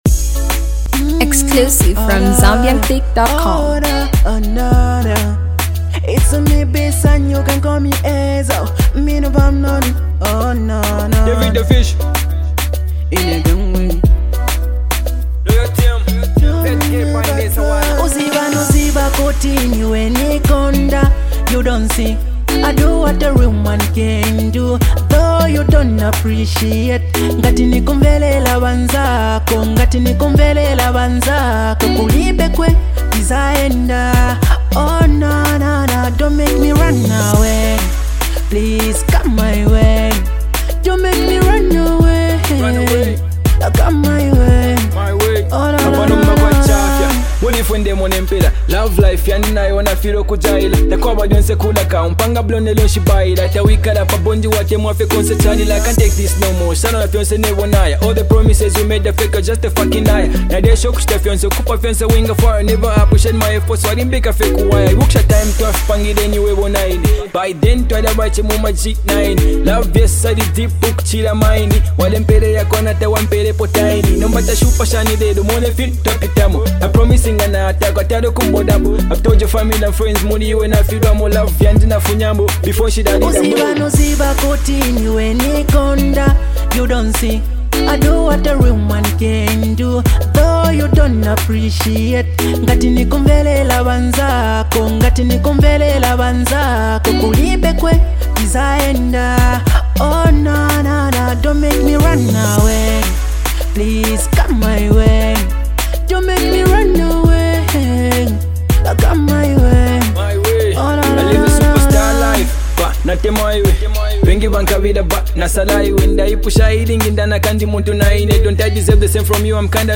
hip hop
new wave vocalist